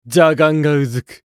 厨二病ボイス～戦闘ボイス～
【交代ボイス(出)2】